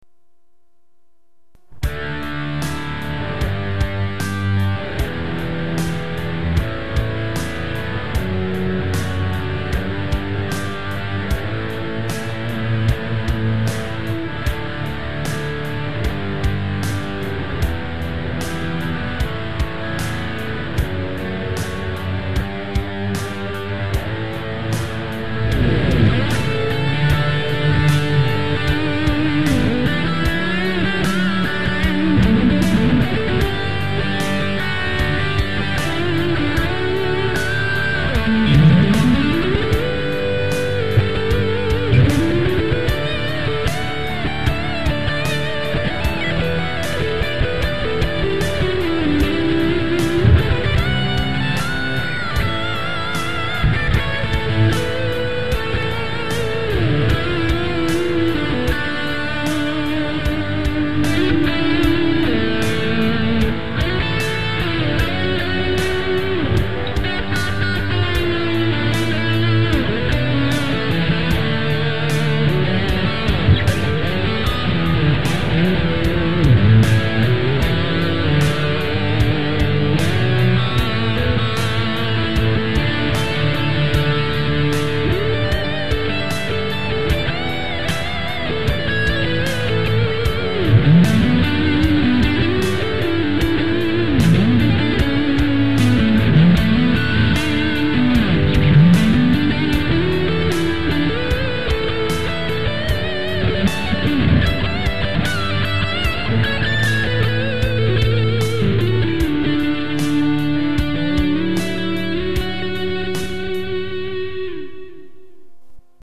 Tak som nahral teda vlastnu improvizaciu, trochu bieda, ale nevadi...
Je z toho síce vežmi cítiť Petrucci, ale zatiaž to nevadí.